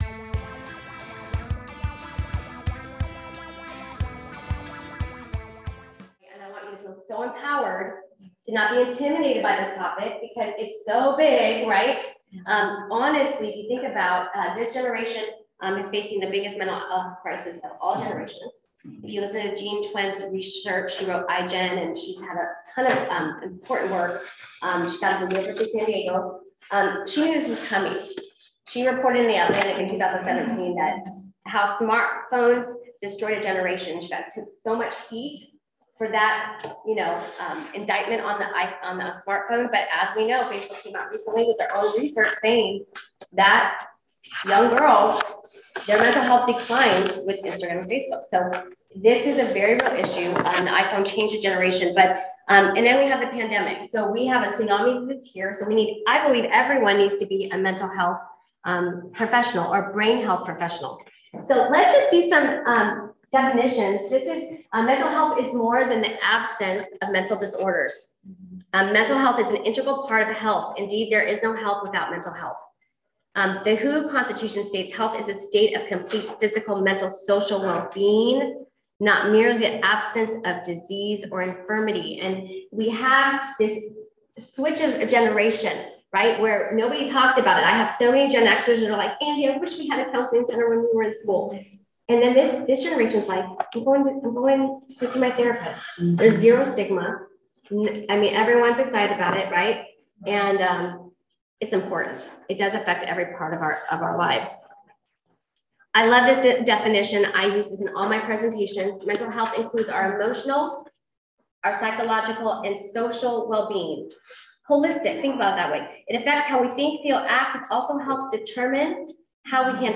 Workshop recording from the 2022 Foursquare Leadership and Education Forum.